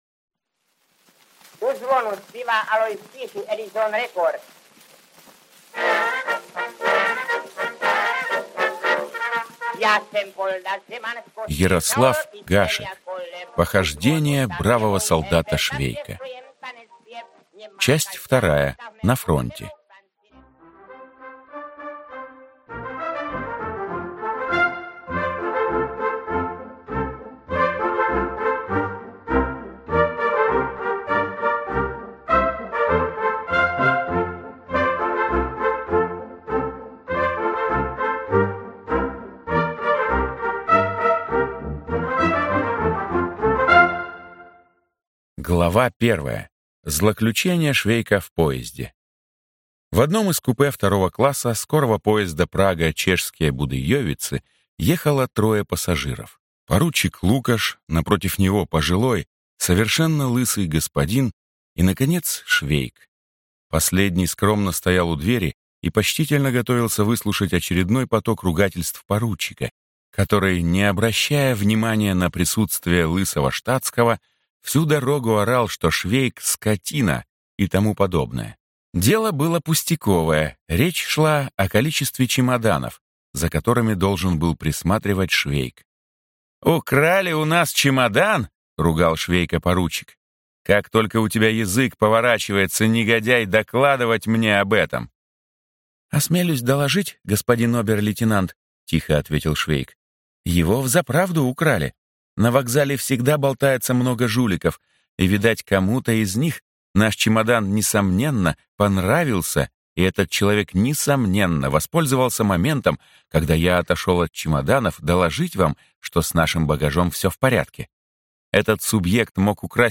Аудиокнига Похождения бравого солдата Швейка. Часть 2 | Библиотека аудиокниг